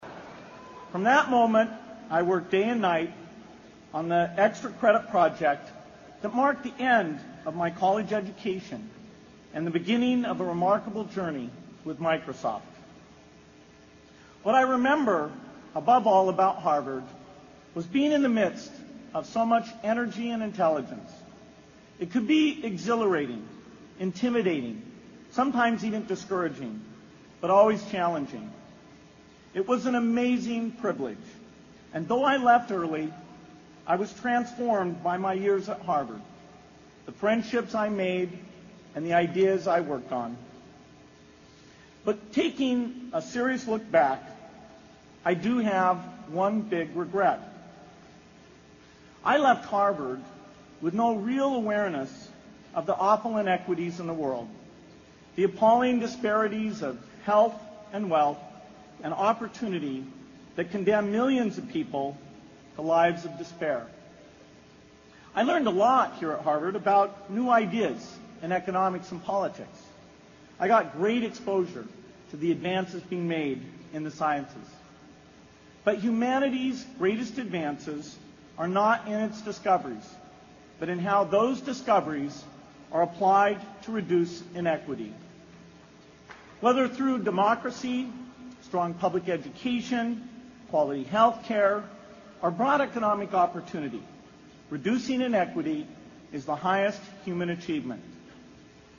名校励志英语演讲 27:如何解决这个世界上最严重的不平等?